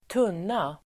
Uttal: [²t'un:a]